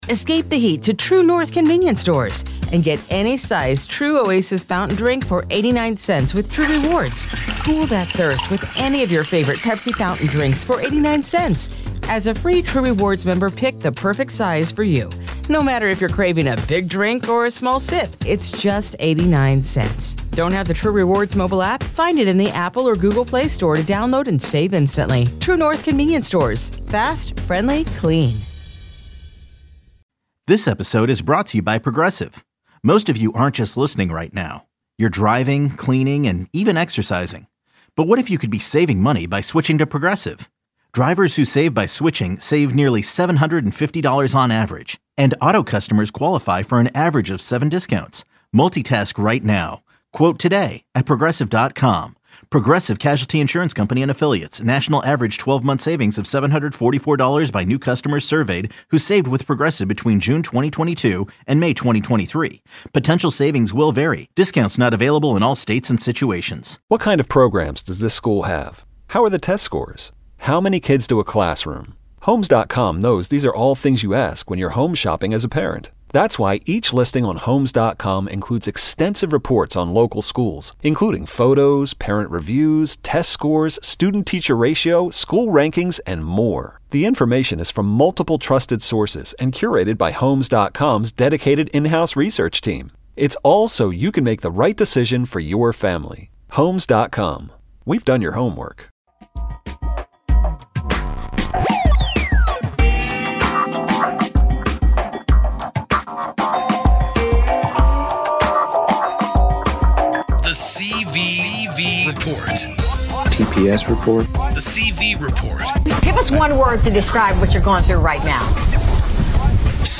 We also talked with Senator Angus King about his recent trip to Iraq, and what he plans on doing to address the veteran suicide crisis.